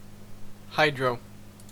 Ääntäminen
Synonyymit hydroaeroplane Ääntäminen CA CA : IPA : /ˈhaɪdro/ IPA : /ˈhʌɪdro/ Haettu sana löytyi näillä lähdekielillä: englanti Kieli Käännökset suomi vesivoima Hydro on sanan hydroaeroplane lyhenne (ilmailu).